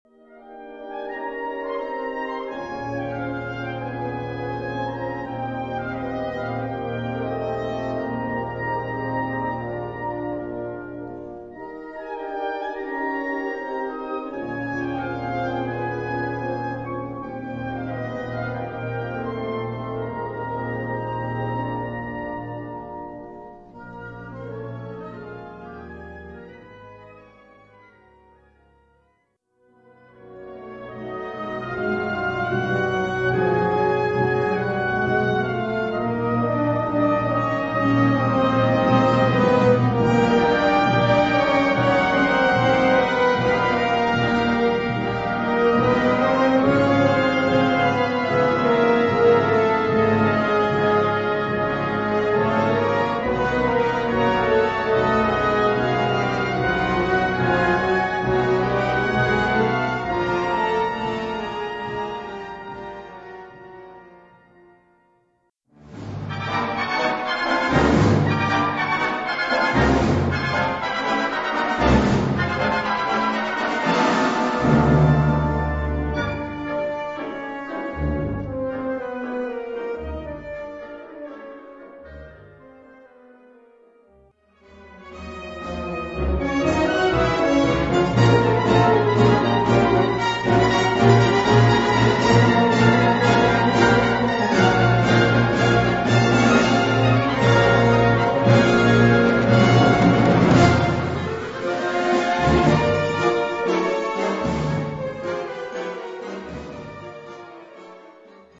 Categorie Harmonie/Fanfare/Brass-orkest
Subcategorie Suite
Bezetting Ha (harmonieorkest)